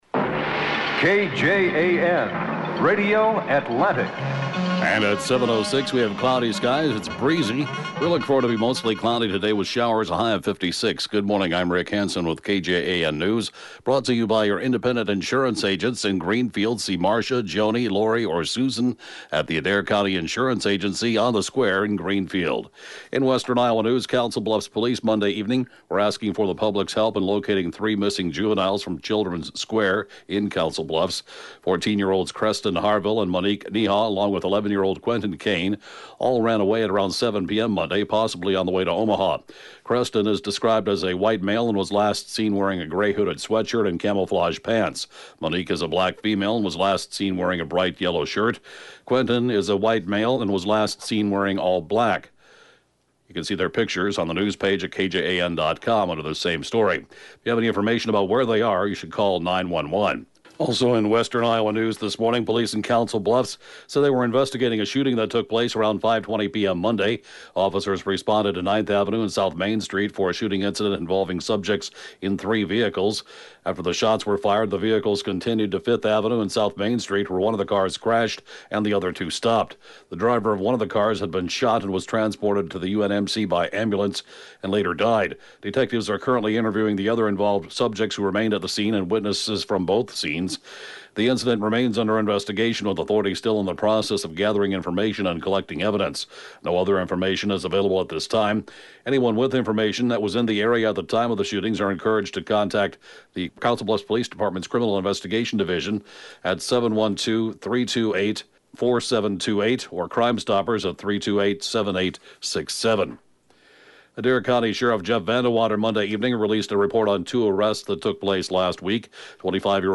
(Podcast) KJAN Morning News & Funeral report, 5/7/2019